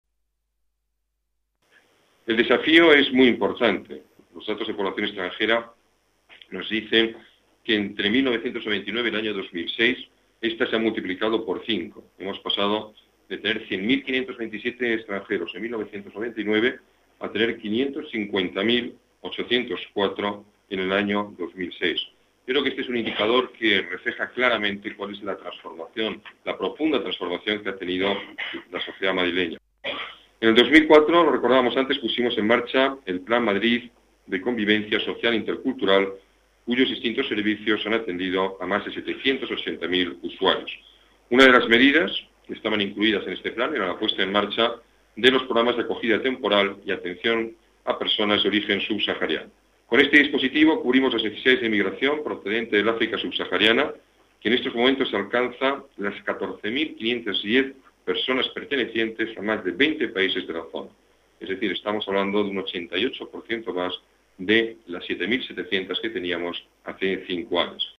Nueva ventana:Declaraciones del alcalde: Datos